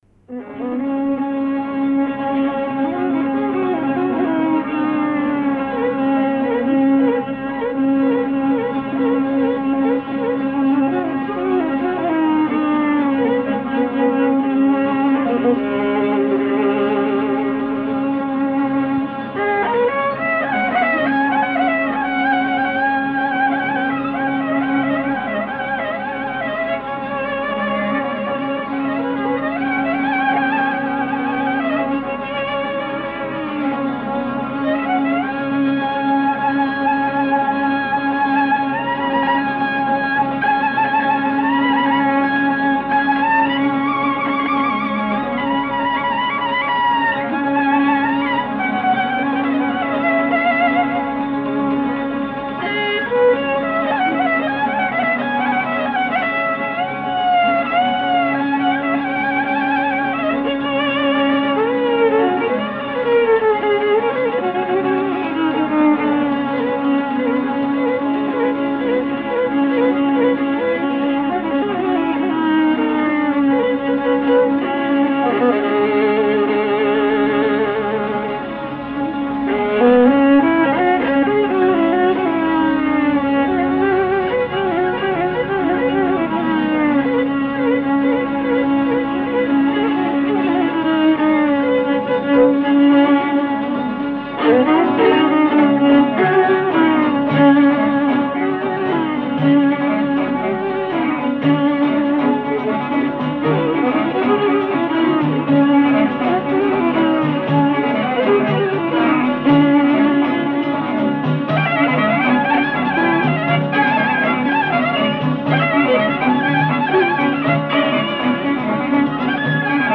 Kaba e llojit dy pjesëshe: kaba dhe valle. Kabaja në pjesën e parë përshfaqet në llojin e kabasë tipike vajtuese mbi motive të Labërisë.
Pjesa e dytë, vallja e përforcon kontrastin midis pjesëve për shkak të ritmikës insistuese dhe asaj që populli  e konsideron pedal fiks, ose vango-vango. Në këtë rast përdoren shkallët muzikore pentatonike hemitonike, përkundër shkallëve pentatonike anhemitonike tek kabaja në pjesën e parë.
5.-Kaba-laberishte_-Sazet-e-Radios_-1966.mp3